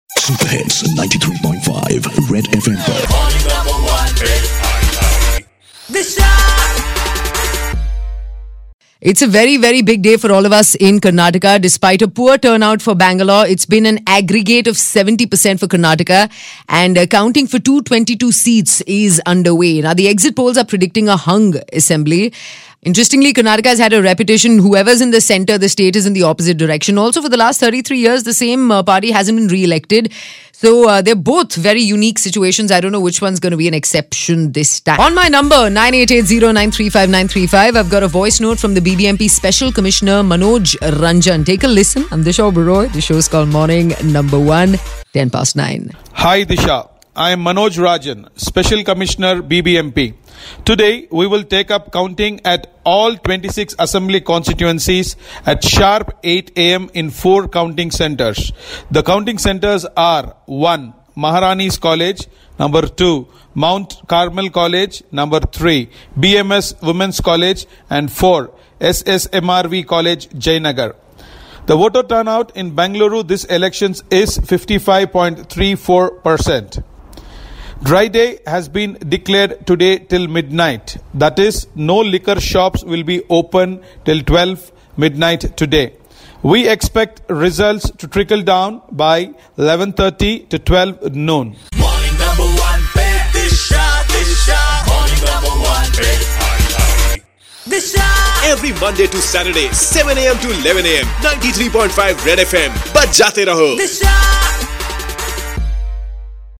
BBMP Special Commissioner Manoj Rajan giving details of Election Counting Day